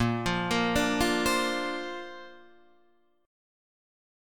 A#add9 chord {6 8 8 7 6 8} chord